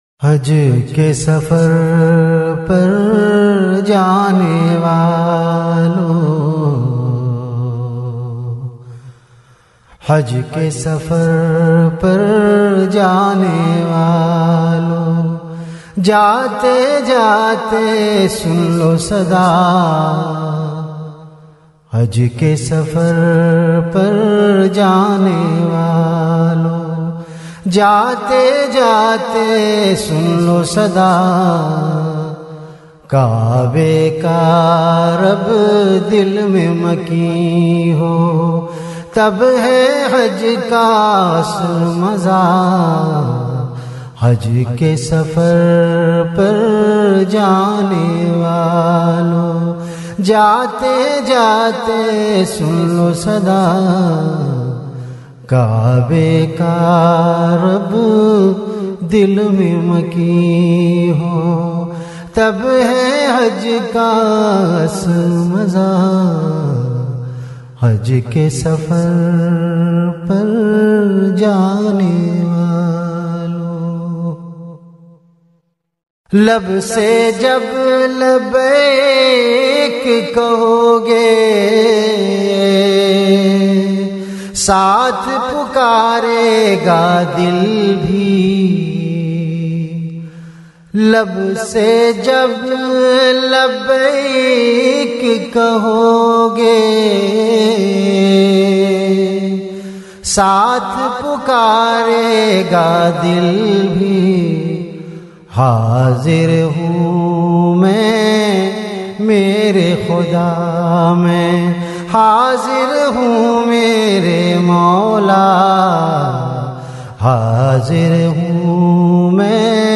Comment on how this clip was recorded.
Venue Home Event / Time After Isha Prayer